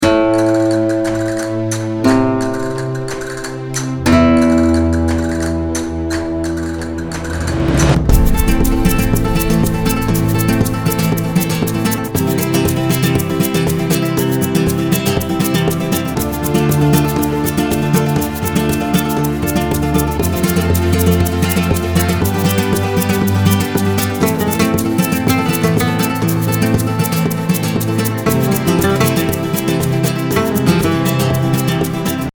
World: Flamenco Pop